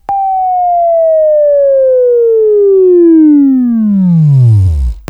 Sweeping training
Buzz